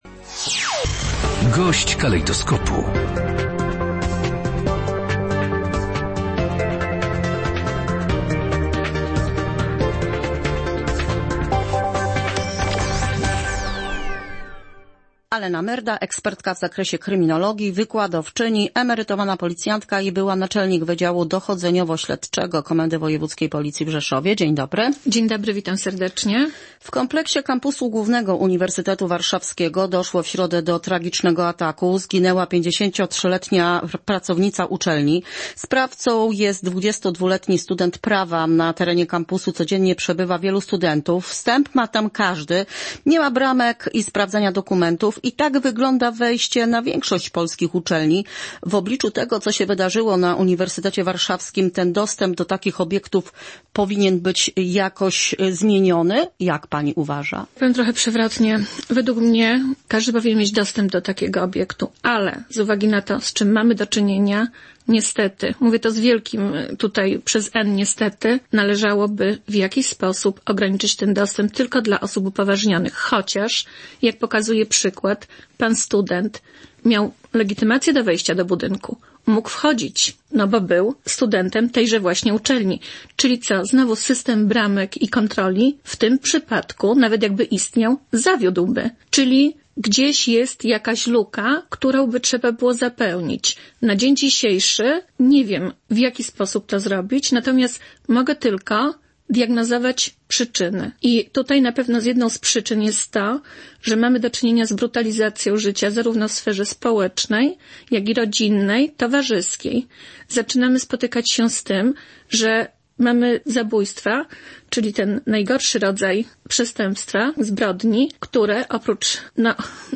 Gość dnia • Brutalizacja życia to główne przyczyny tragedii, do których doszło w Warszawie i Krakowie